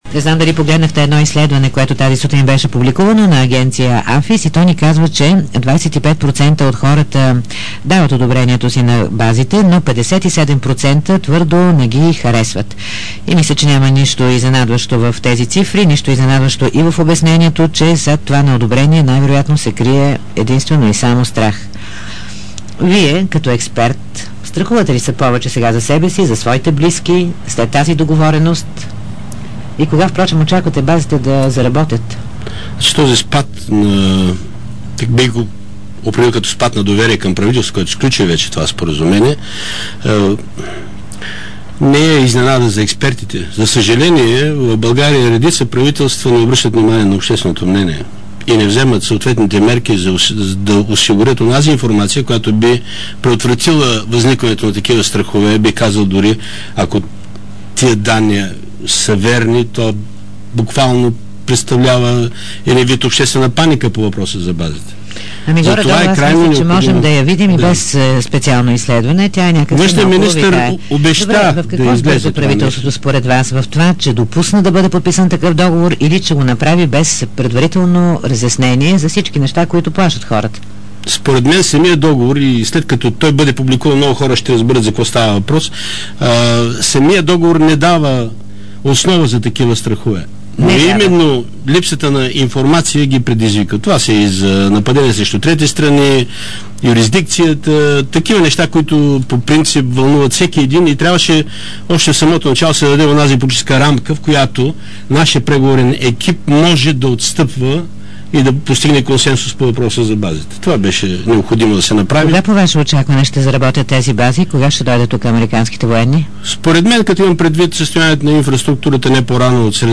Ген. Аню Ангелов, военен експерт и бивш началник на Военната академия “Г. С. Раковски” в предаването „Дарик кафе”